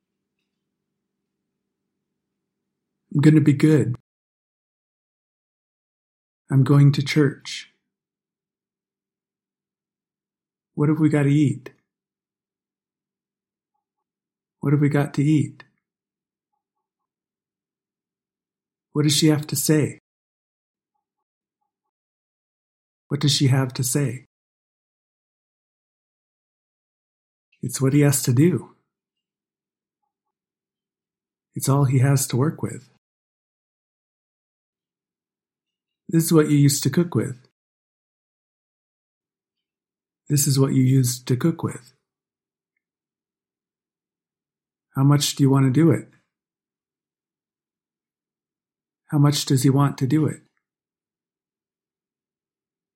Lesson 15 – Contractions – American English Pronunciation
Non-standard contractions